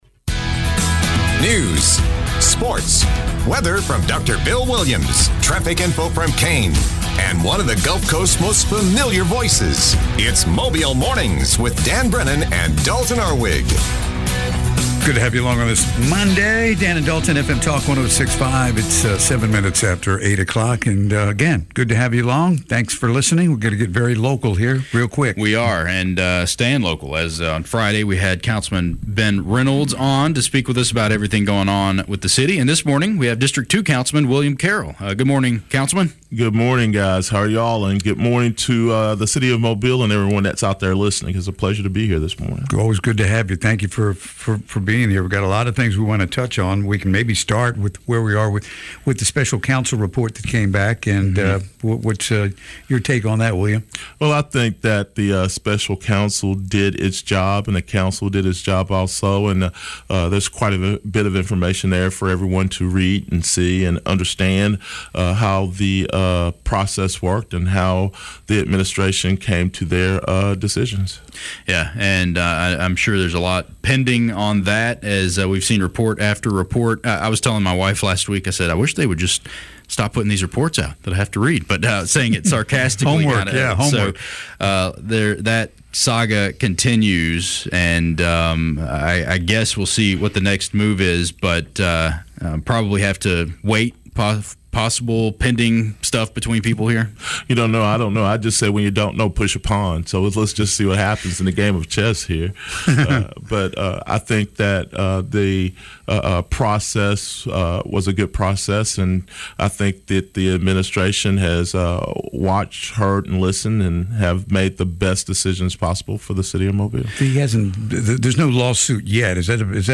Mobile City Councilman William Carroll talked about Amtrak and other projects - Mobile Mornings - Monday 7-29-24